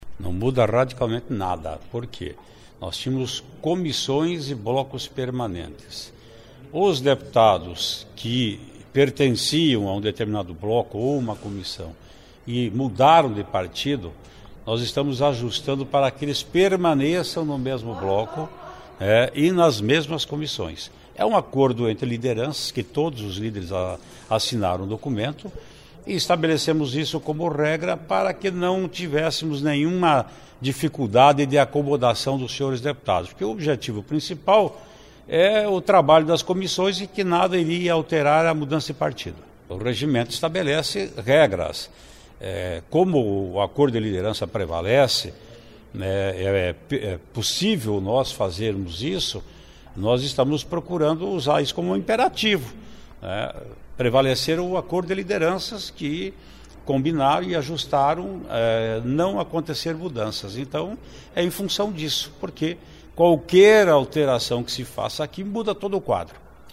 Ouça a entrevista com o preisdente da Assembleia Legislativa, deputado Ademar Traiano (PSDB), sobre o projeto.
(sonora)